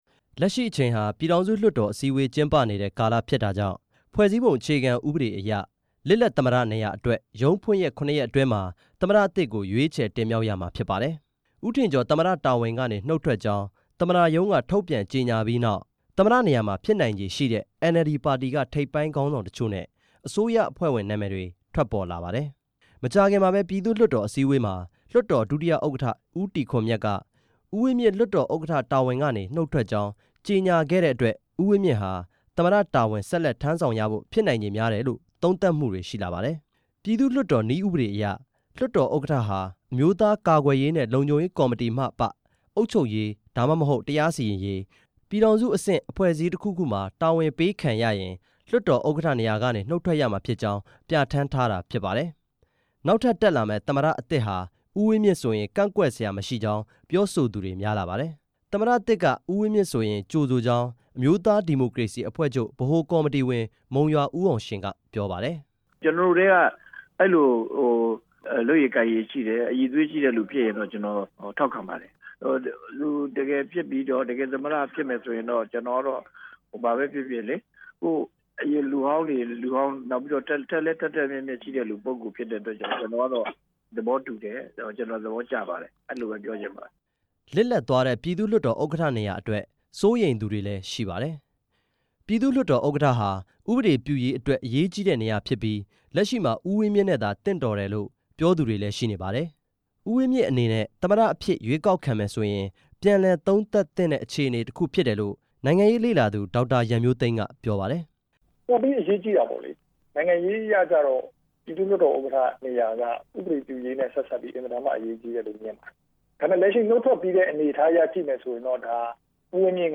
နိုင်ငံရေး လေ့လာသူတွေ၊ NLD ပါတီတာဝန်ရှိသူတွေနဲ့ တိုင်းရင်းသားခေါင်းဆောင်တချို့ရဲ့ ပြောကြားချက်တွေကို